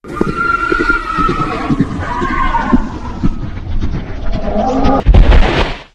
headless_horseman_dies.ogg